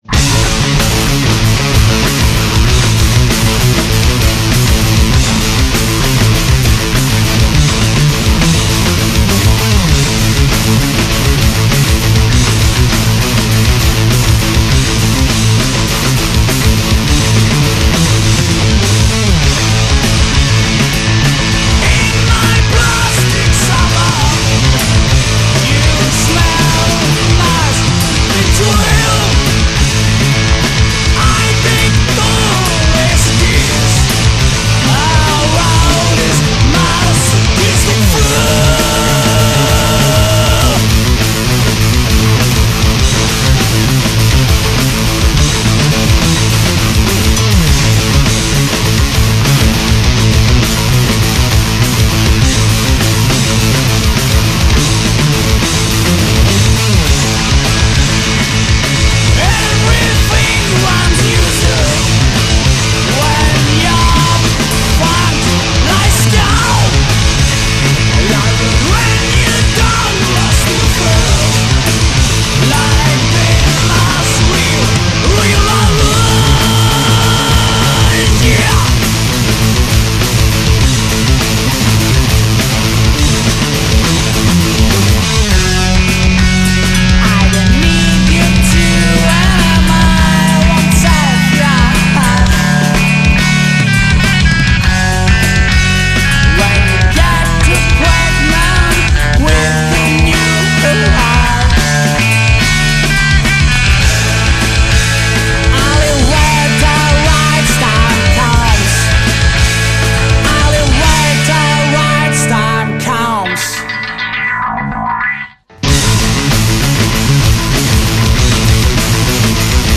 vocal, guitar
bass
drums
Backing vocals